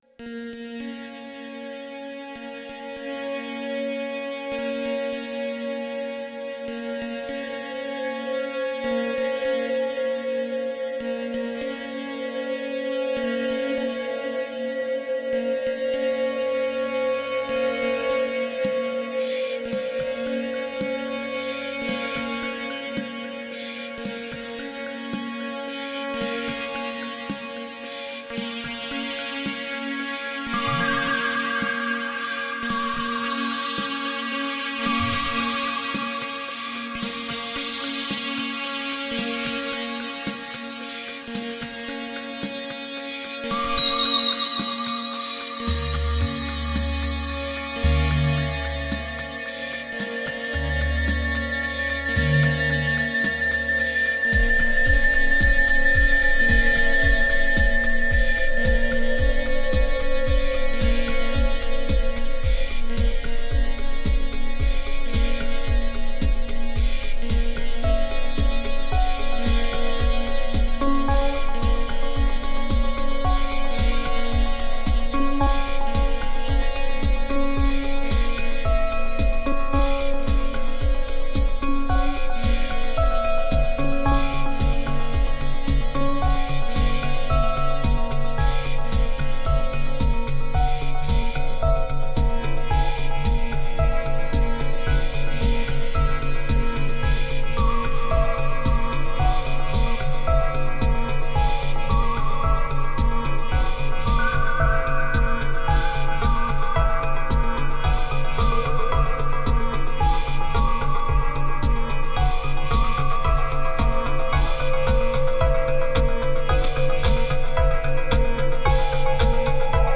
2364📈 - 81%🤔 - 111BPM🔊 - 2014-09-26📅 - 511🌟